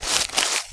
STEPS Bush, Walk 03.wav